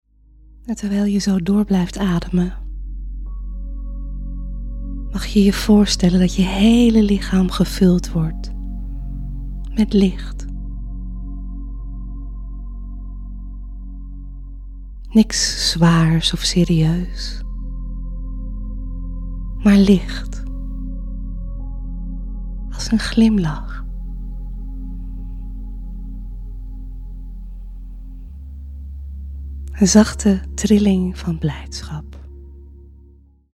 Mini-meditatie
Kort, licht en speels – voor als je even wilt landen, opfrissen, of gewoon weer een sprankje zin wilt voelen.